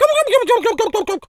turkey_ostrich_gobble_14.wav